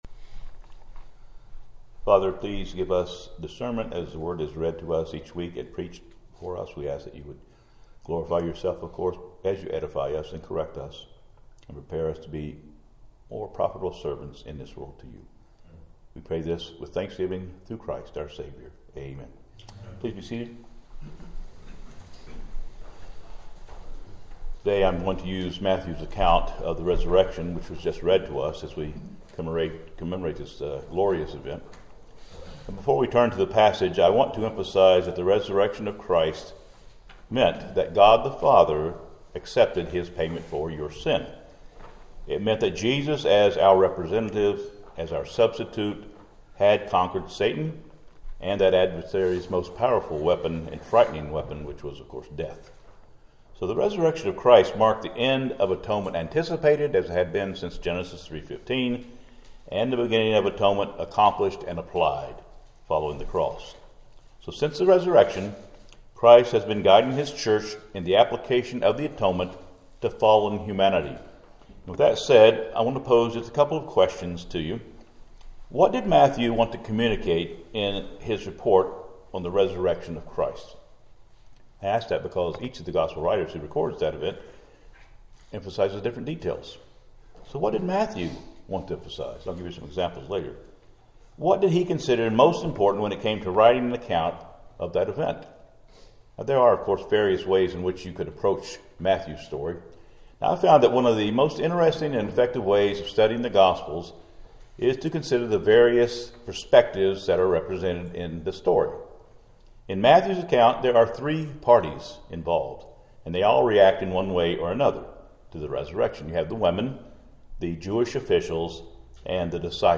Matthew 28 Service Type: Sunday Service SResurrection Sun Topics